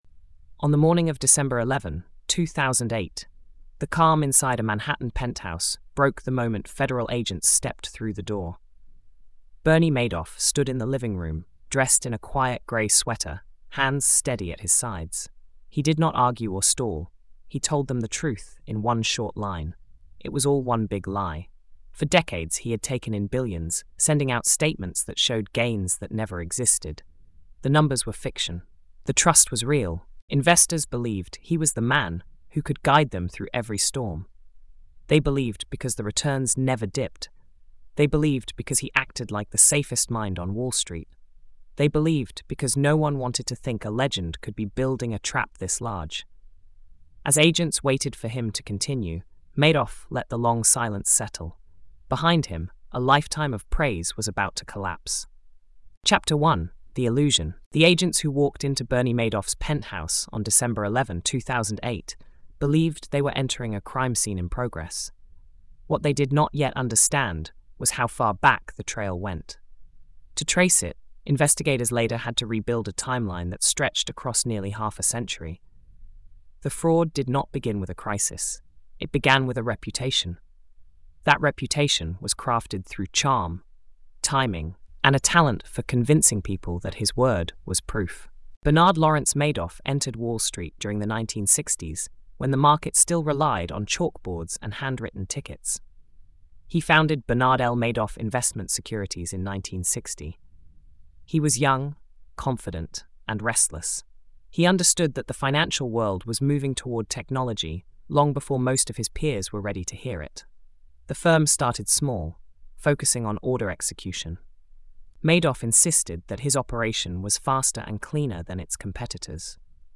The King of All Ponzis: Bernie Madoff is a three-chapter nonfiction forensic series that breaks down the rise and destruction of the largest Ponzi scheme in modern financial history. Told in a clear, cinematic documentary style, the series follows the arc from Madoff’s carefully built reputation to the decades-long deception that devastated investors around the world. Chapter 1 reveals how Madoff used charm, steady returns, exclusivity, and industry prestige to shield himself from scrutiny.